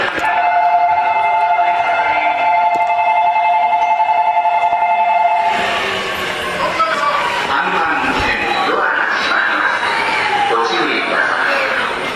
ベル 聞いたことあるような音色…